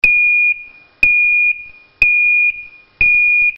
Zumbador Electrónico Empotrable ø 28 mm
90 dB (80 dB con protector)
Intermitente
Intermitente.mp3